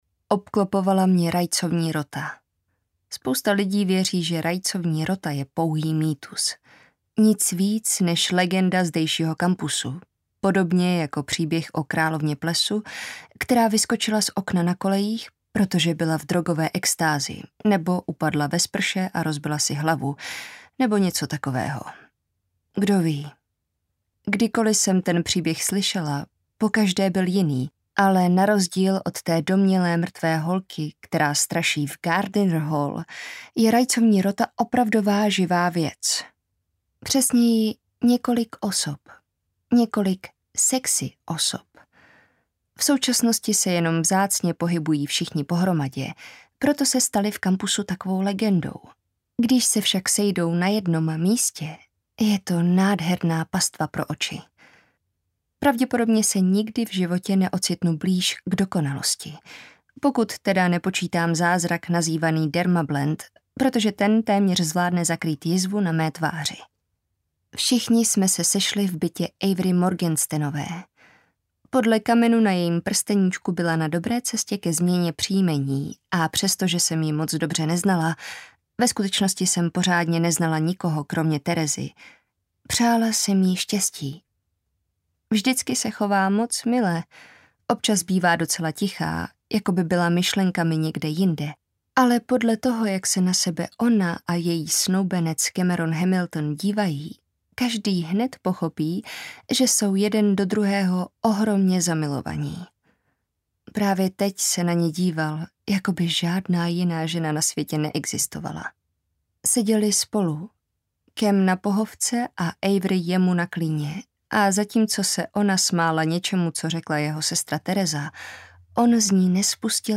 Zůstaň se mnou audiokniha
Ukázka z knihy